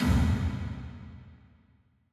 Index of /musicradar/layering-samples/Drum_Bits/Verb_Tails